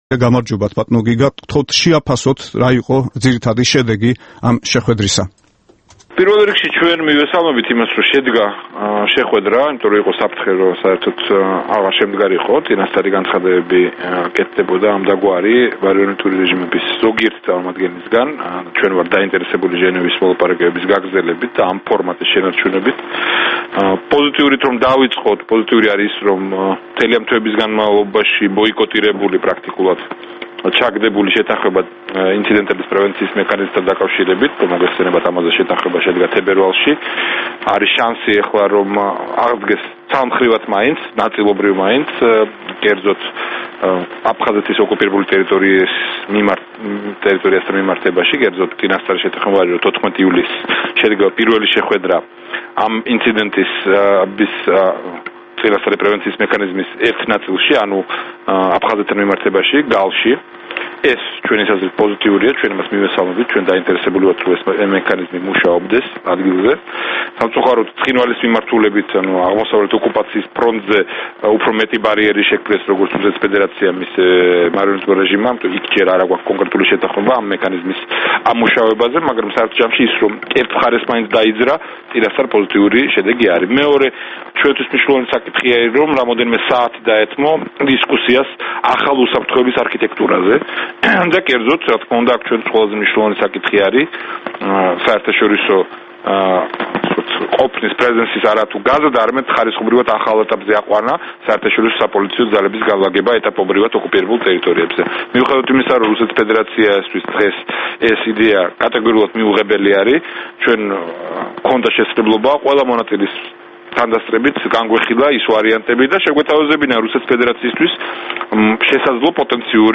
ინტერვიუ გიგა ბოკერიასთან